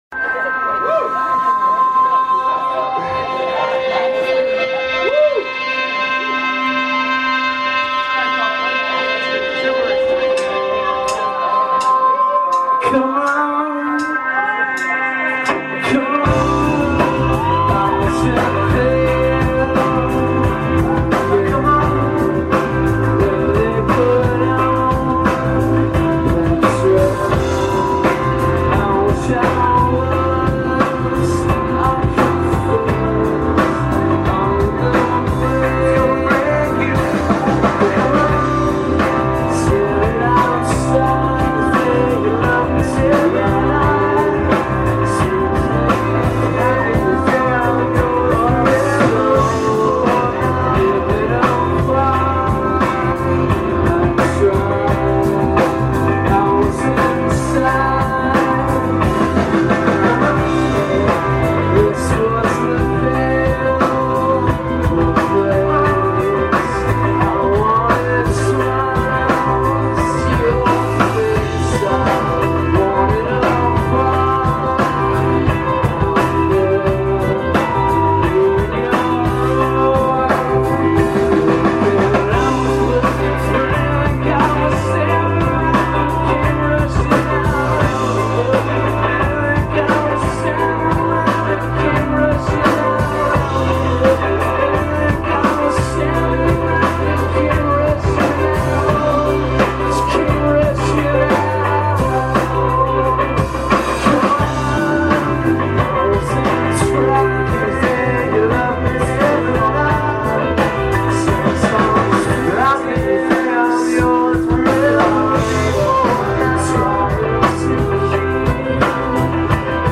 tt the bear's cambridge april 5 2002